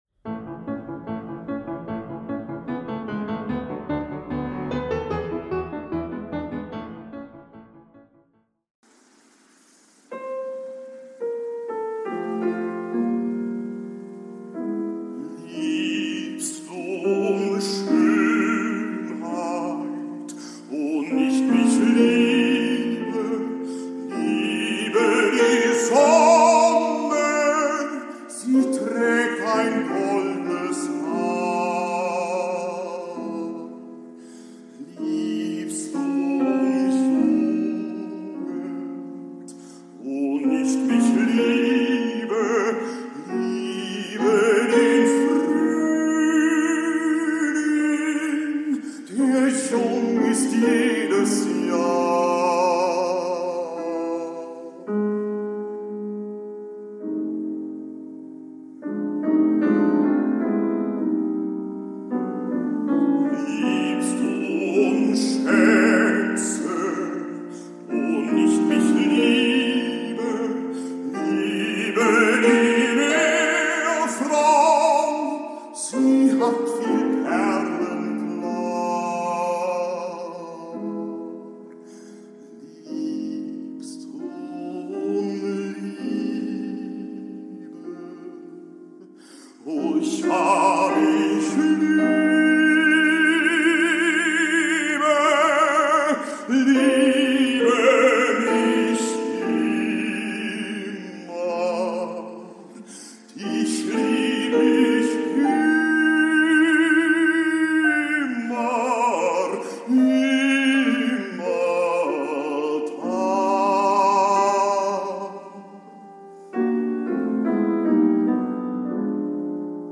Hört das ausführliche Gespräch mit dem Konzert- und Opernsänger Hanno Müller-Brachmann, Auszüge aus einem seiner Liedkonzerte, eine Gedichtrezitation u.a.m. Für alle, die die Liedkunst und die klassische Musik lieben.